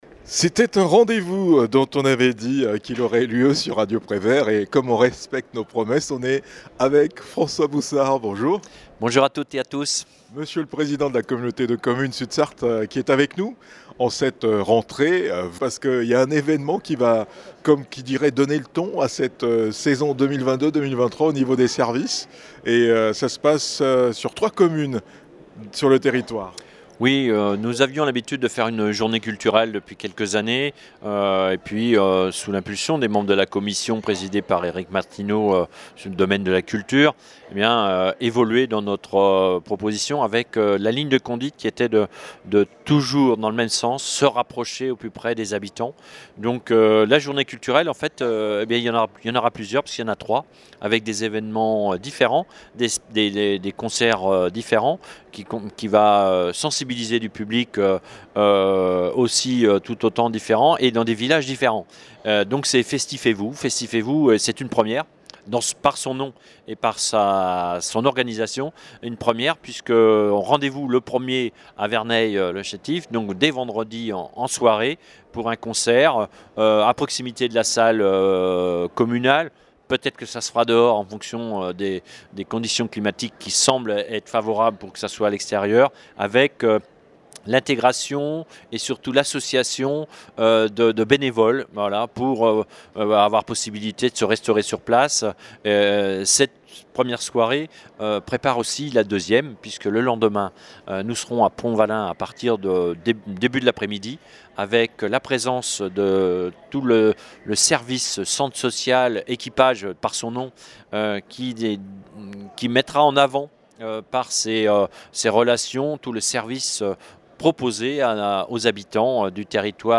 L'événement organisé par la Communauté de communes Sud Sarthe réunit une large gamme de propositions culturelles : spectacles, fanfares & musiques municipales, expositions et animations assurées par les associations culturelles locales. François Boussard, le président de l'intercommunalité, présente les temps forts de Festif & Vous !